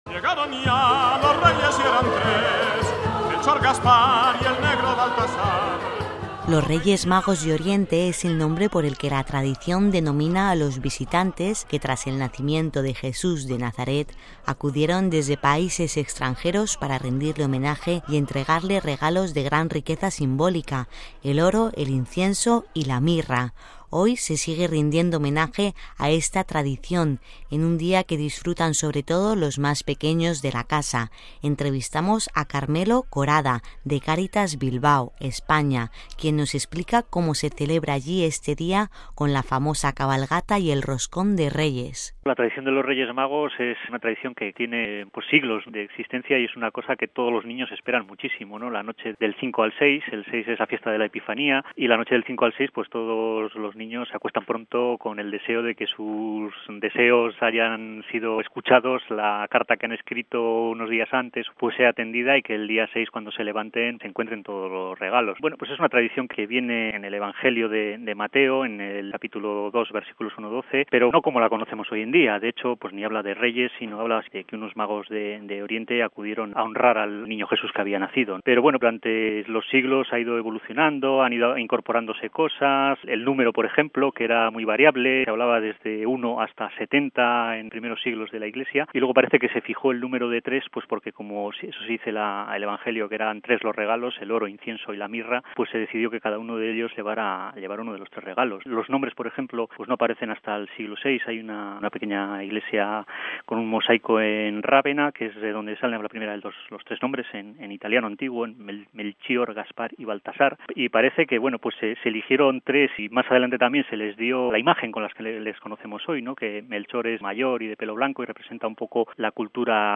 Hoy se sigue rindiendo homenaje a esta tradición, en un día que disfrutan sobre todo los más pequeños de la casa. Entrevistamos